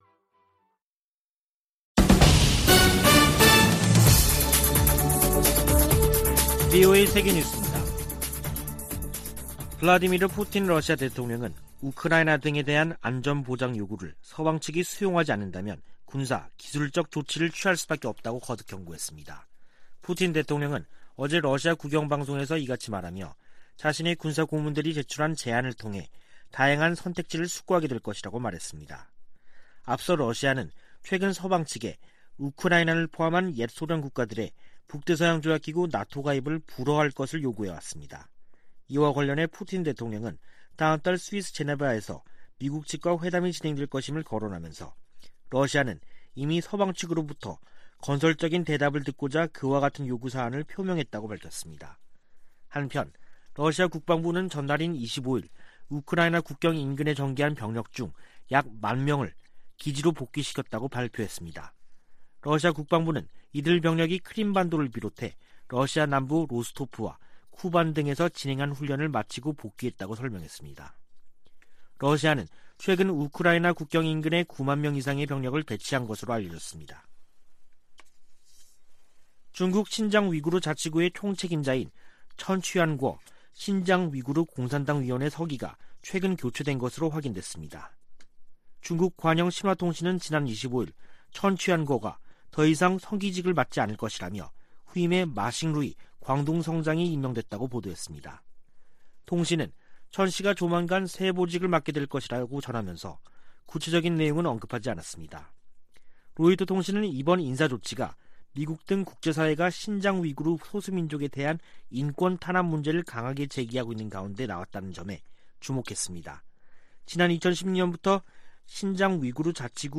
VOA 한국어 간판 뉴스 프로그램 '뉴스 투데이', 2021년 12월 27일 3부 방송입니다. 2022년 새해를 앞두고 조 바이든 미국 행정부의 대북 전략에 대한 전문가들의 전망과 제언이 이어지고 있습니다. 로버트 에이브럼스 전 주한 미군사령관은 미국과 한국이 연합훈련 일부 재개를 진지하게 논의할 시점이라고 말했습니다. 북한이 일부 경제 부문의 성과를 부각하며 올 한 해를 승리의 해라고 연일 선전하고 있습니다.